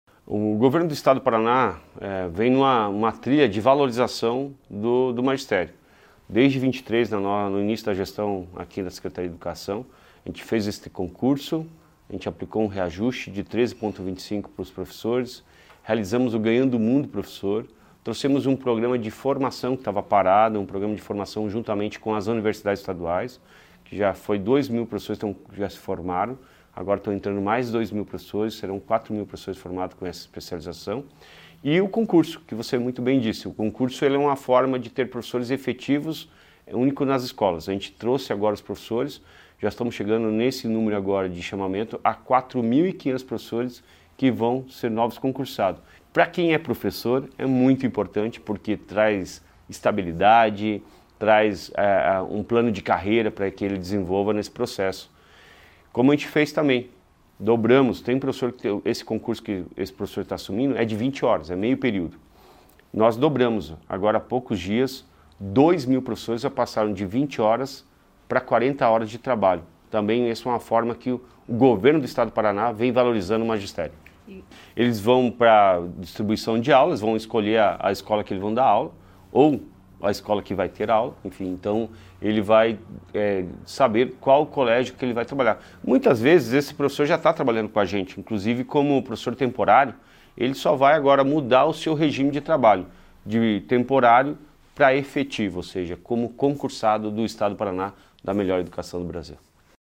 Sonora do secretário da Educação, Roni Miranda, sobre a nomeação de mais 988 professores do concurso público de 2023